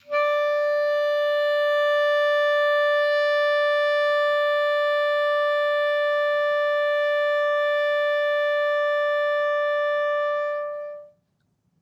Clarinet
DCClar_susLong_D4_v3_rr1_sum.wav